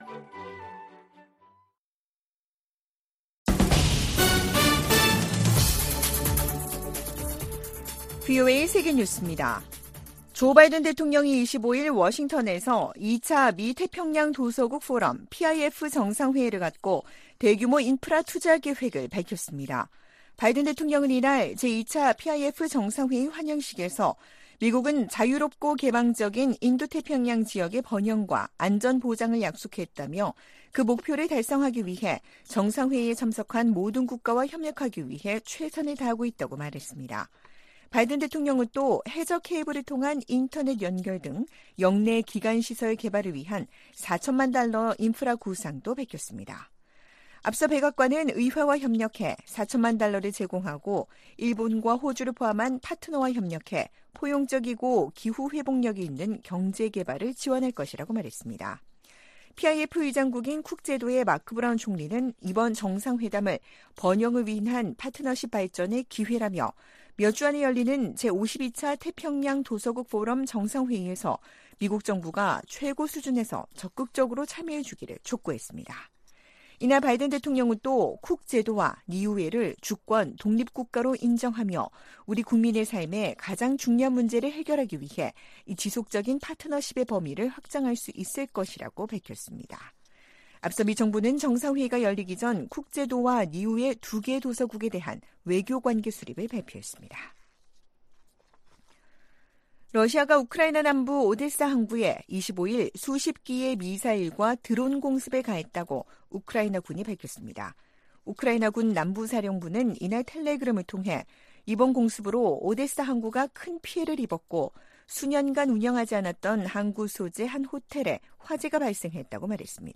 VOA 한국어 아침 뉴스 프로그램 '워싱턴 뉴스 광장' 2023년 9월 26일 방송입니다. 조 바이든 미국 대통령은 러시아가 우크라이나의 평화를 가로막고 있다면서 이란과 북한으로부터 더 많은 무기를 얻으려 하고 있다고 비판했습니다. 시진핑 중국 국가주석이 한국 방문 의사를 밝히고 관계 개선 의지를 보였습니다. 미국, 일본, 인도, 호주 4개국이 유엔 회원국에 북한과 무기 거래를 하지 말 것을 촉구했습니다.